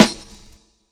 WASHZ_SNR.wav